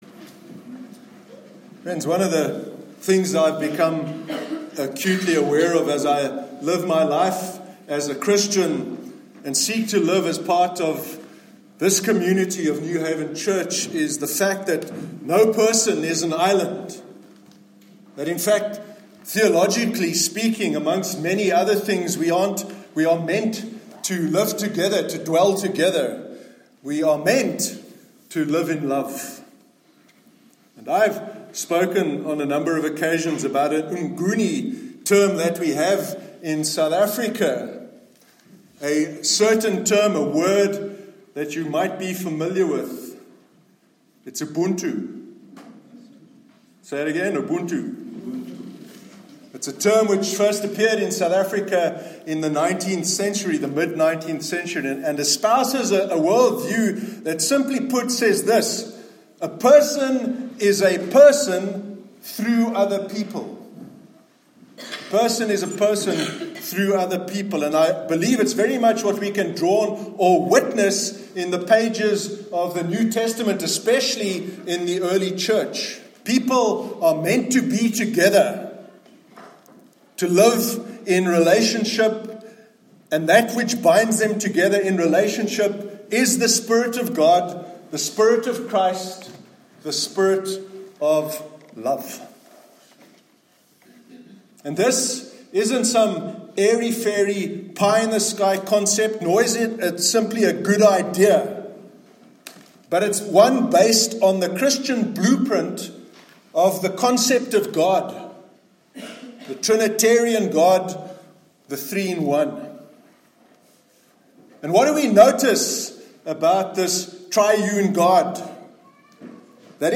Church sermons